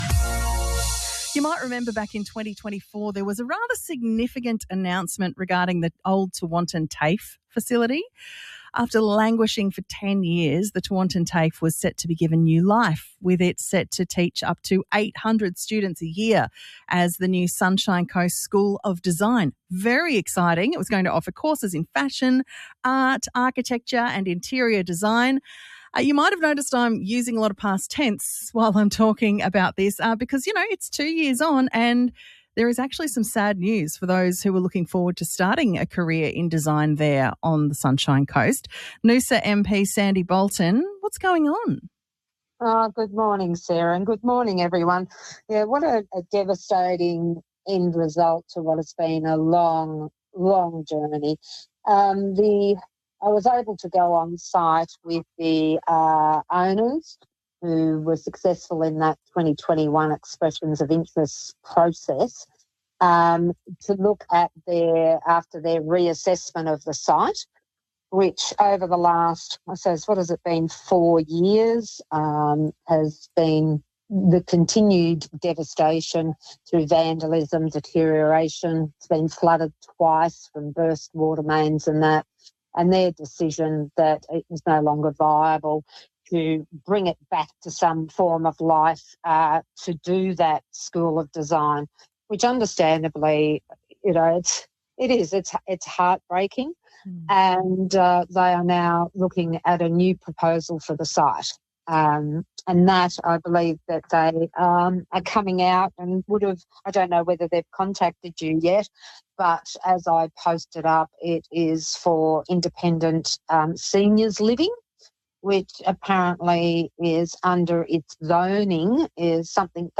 ABS-Sunshine-Coast-TAFE-interview.mp3